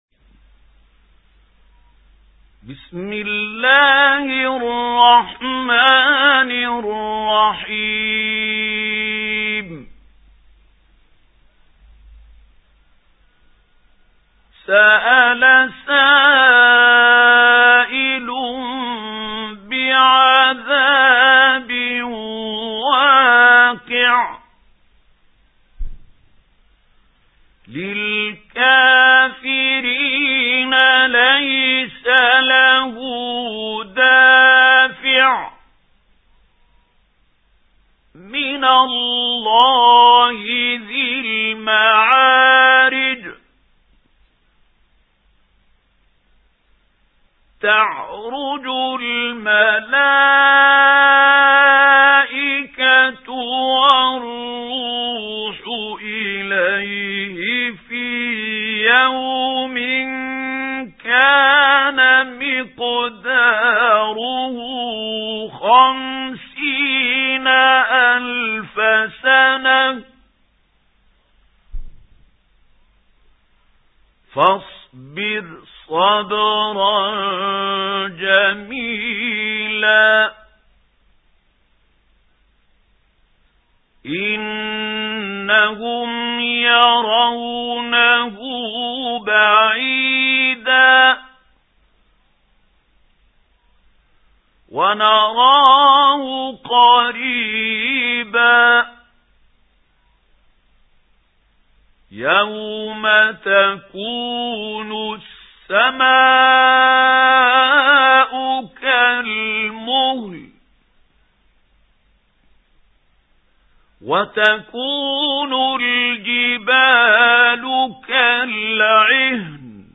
سُورَةُ المَعَارِجِ بصوت الشيخ محمود خليل الحصري